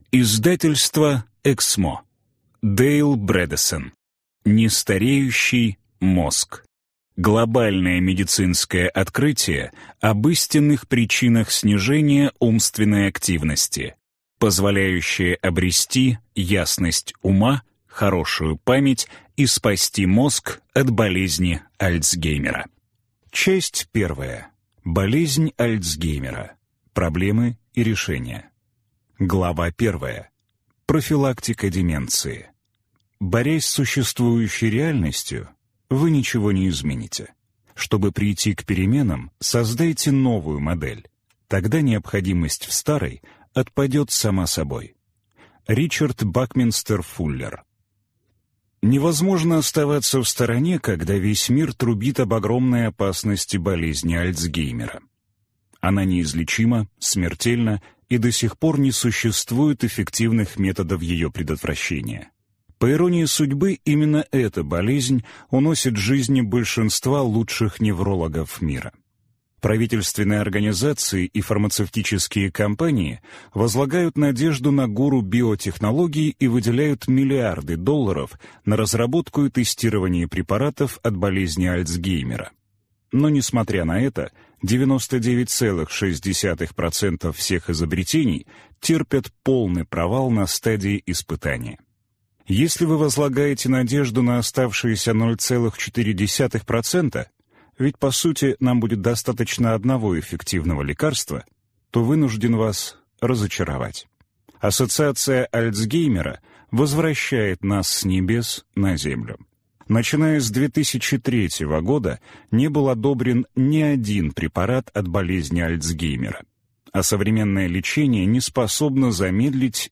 Аудиокнига Нестареющий мозг. Глобальное медицинское открытие об истинных причинах снижения умственной активности, позволяющее обрести ясность ума, хорошую память и спасти мозг от болезни Альцгеймера | Библиотека аудиокниг